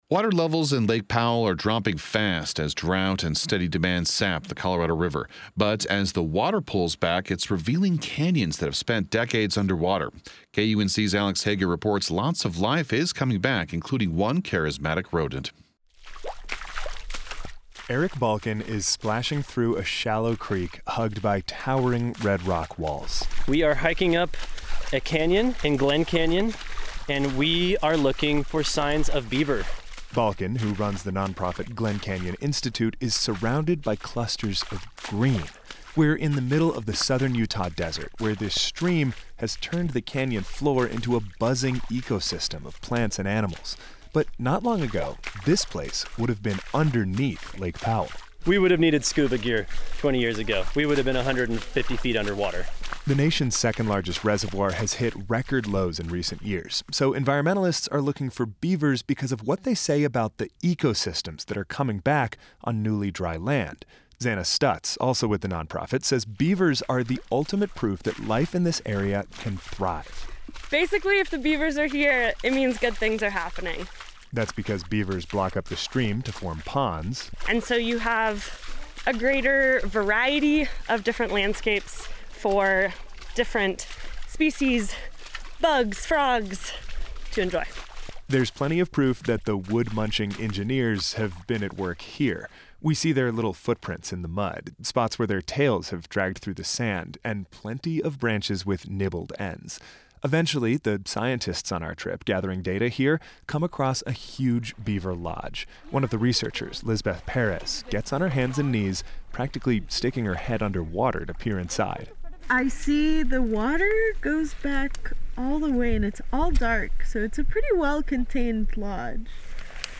The canyon echoed the buzzing and chirping of bugs and toads.